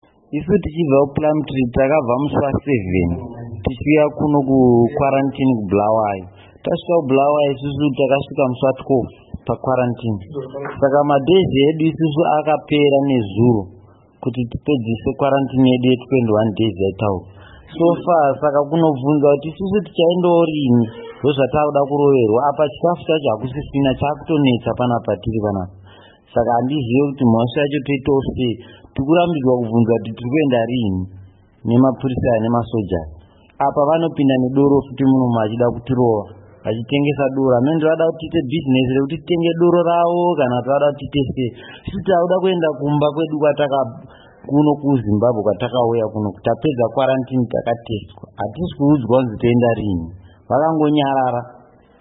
Chizvarwa cheZimbabwe Chichitaura